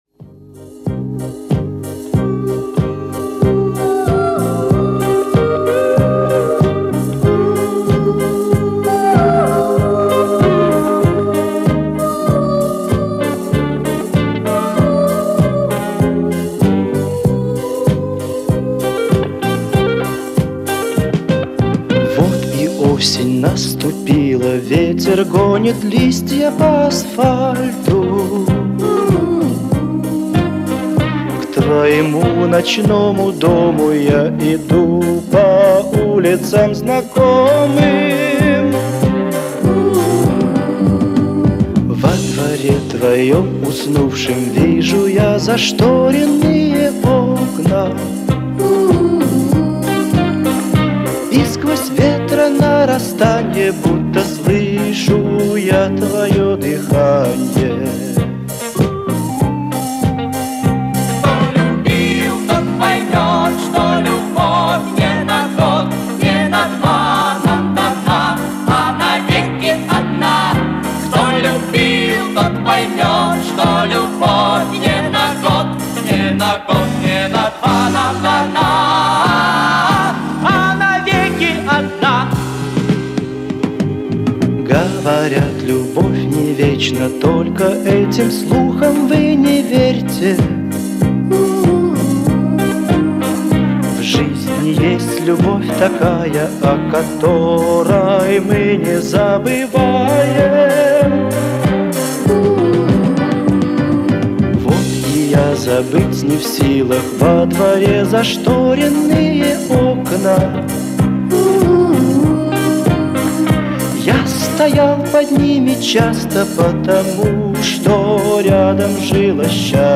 Все же  выложенная запись  больше на ВИА похожа.